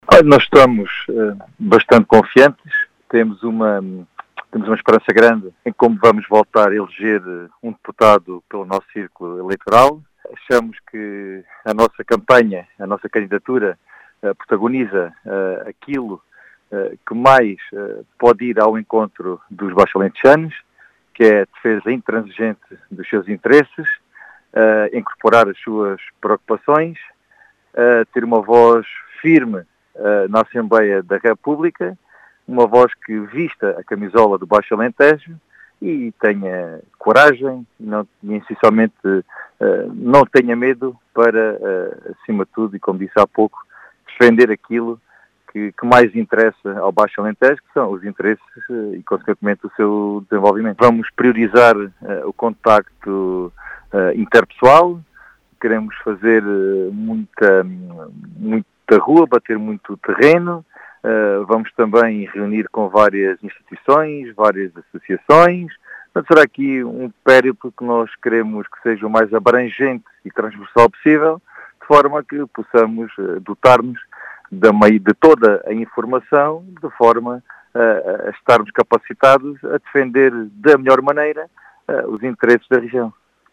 Em declarações à Rádio Vidigueira